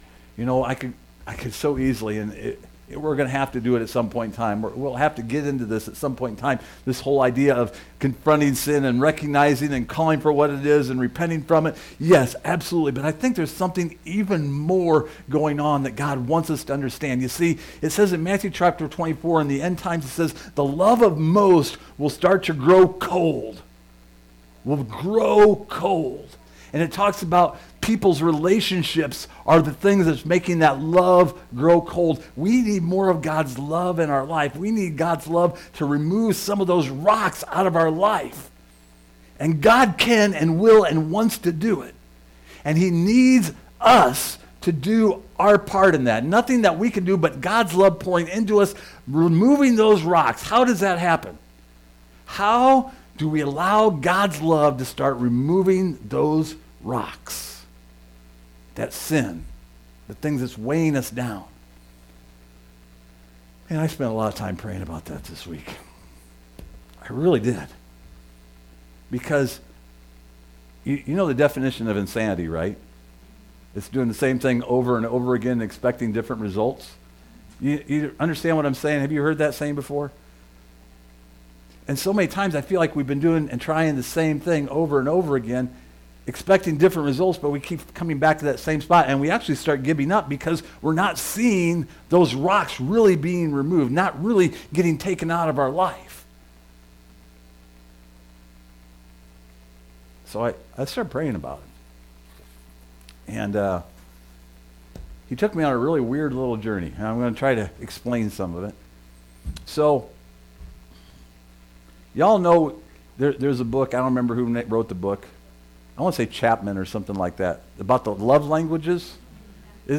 Service Type: Sunday Morning While the Bible teaches